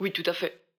VO_ALL_Interjection_16.ogg